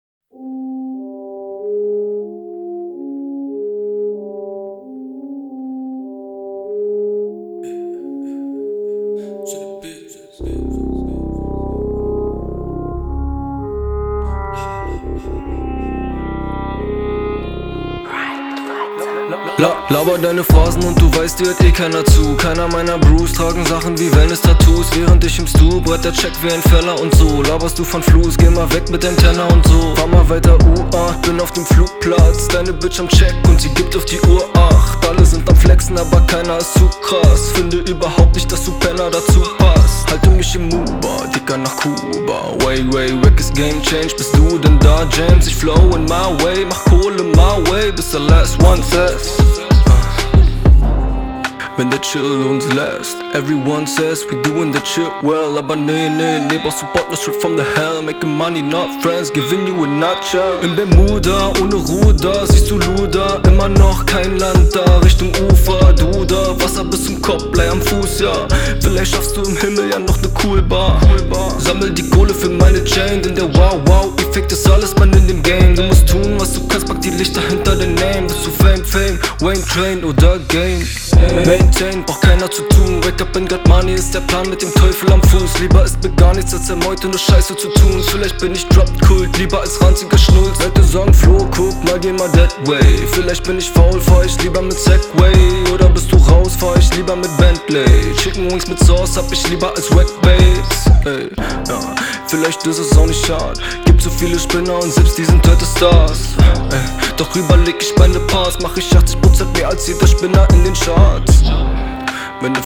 Suche Mastering für Rap Song
Habe jetzt nachträglich mit dem FL Distructor auch ein wenig mehr Zerre rein gebracht.
Und den Beat habe ich zusätzlich etwas dicker gemacht; habe hier noch ein IK Tape rein gebracht um etwas Volume zu generieren. Zudem habe ich versucht ein wenig die Attack herauszuarbeiten, um noch das gewisse etwas an "Smack" zu gewinnen.